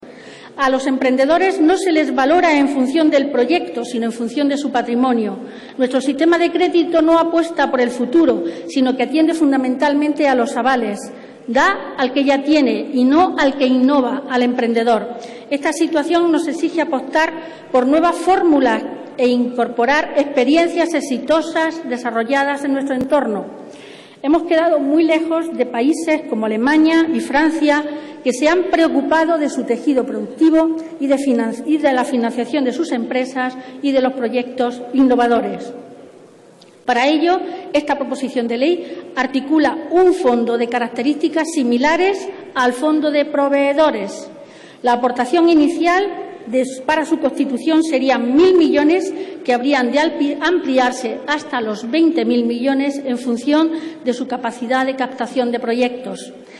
Concha Gutiérrez. Pleno del Congreso. Proposición no de ley para crear un fondo de hasta 20.000 millones con el que financiar a las PYME y a los emprendedores. 12/03/2013